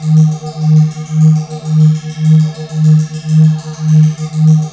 HYPNOTIC.wav